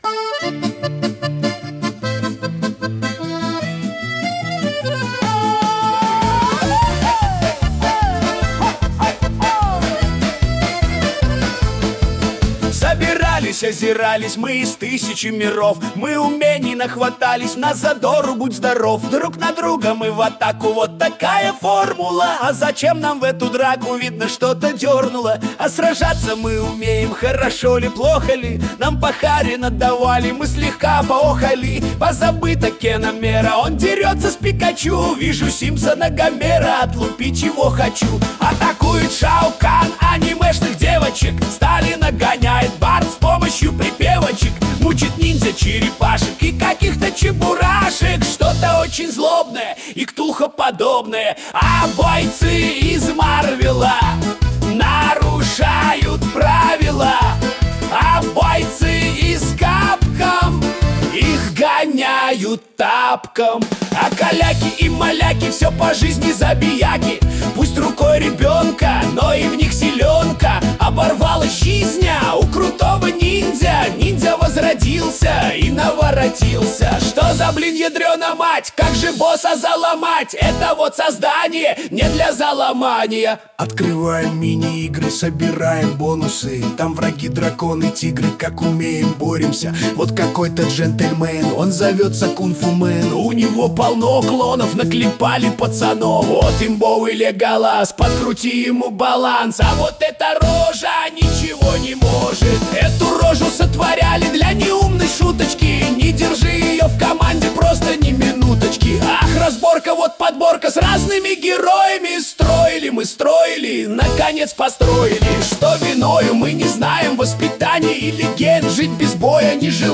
Частушки про Муген